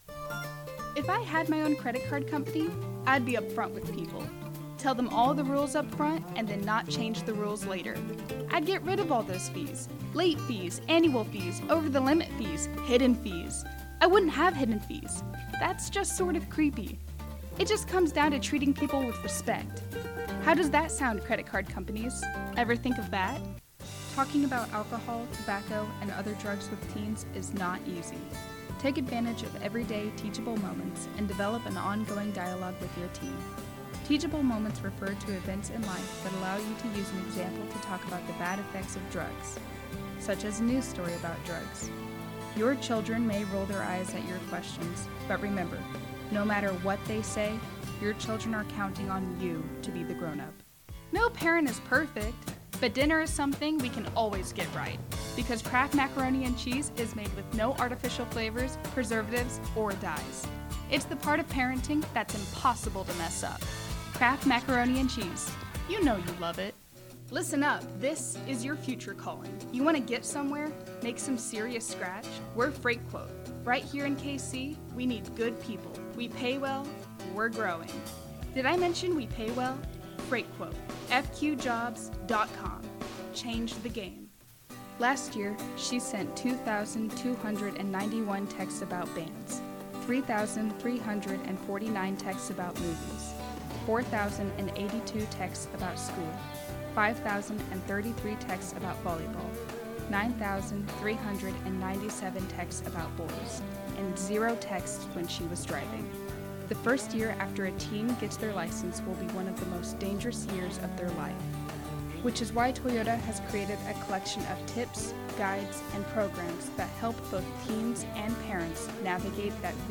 Gender: Female
VO DEMO